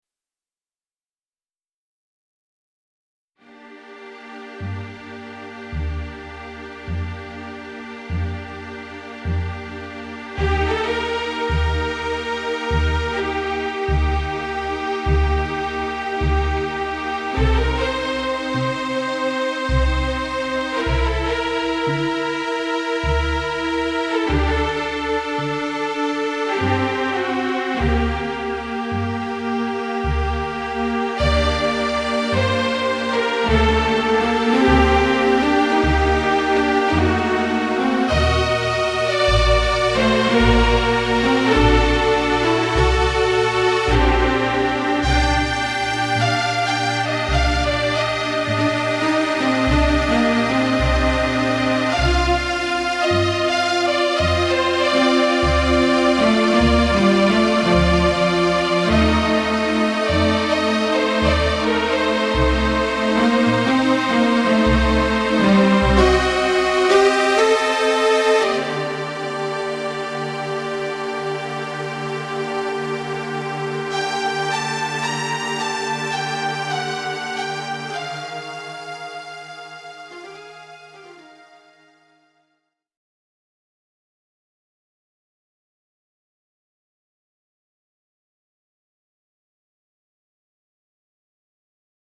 Adagio in G minor - Computer generated
Everything is all synths. I added many volume automation points.